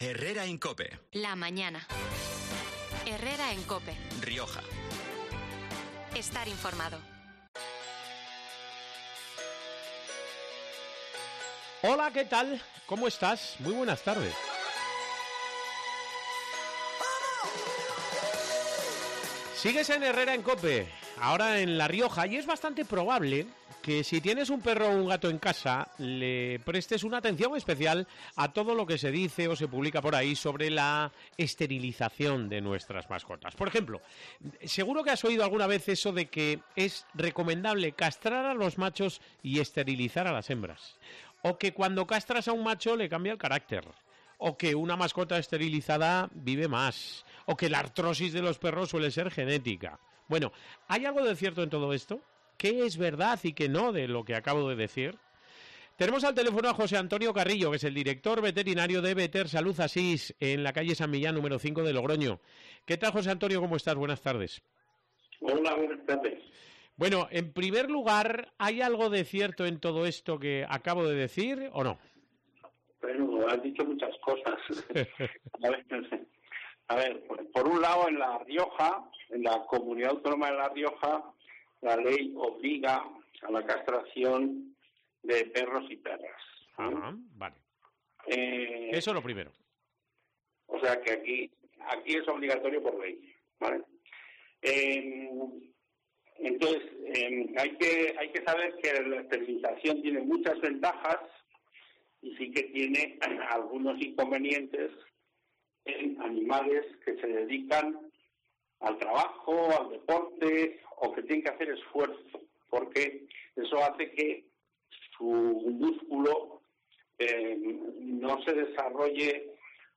Desde allí nos ha atendido por teléfono